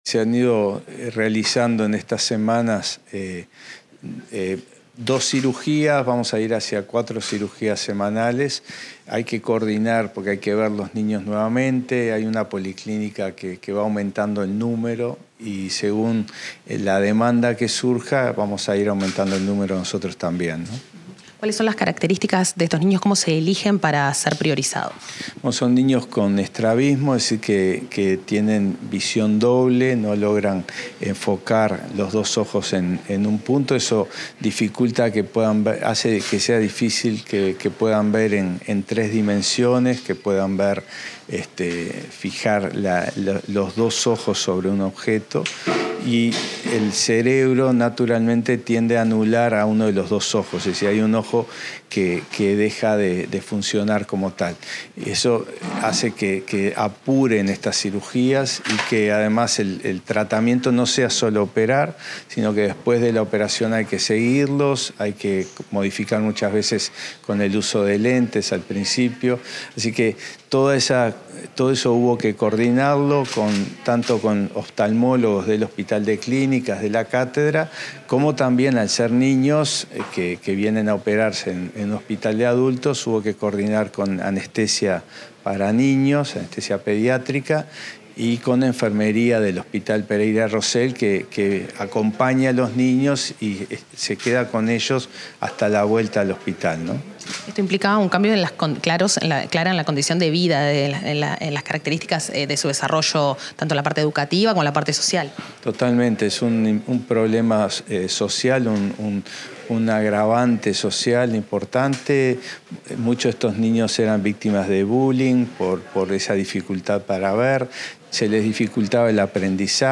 Declaraciones del director del Hospital de Clínicas, Álvaro Villar
El director general del Hospital de Clínicas, Álvaro Villar, efectuó declaraciones, tras dos nuevas cirugías de estrabismo a niños usuarios de la Administración de los Servicios de Salud del Estado en el centro universitario. El jerarca insistió en la relevancia de atender estos casos no solo desde el punto de vista médico, sino también psicológico.